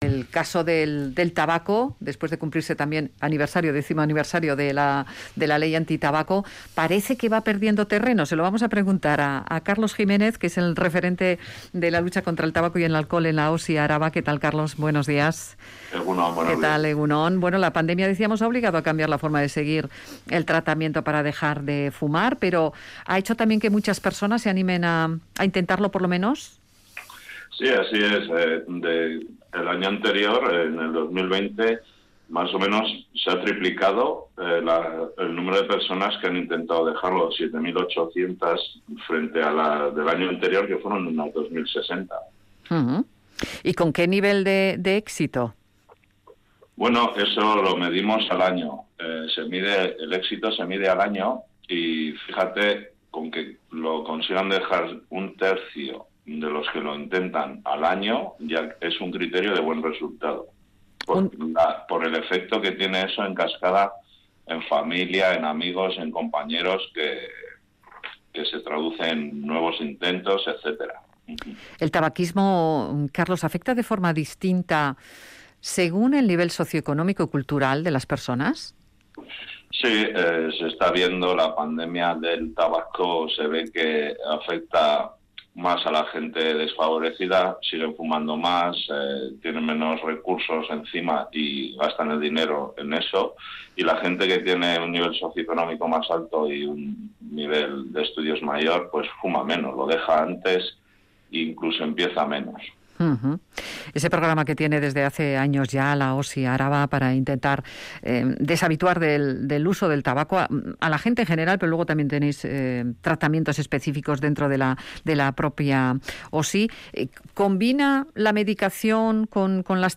Entrevistado en Radio Vitoria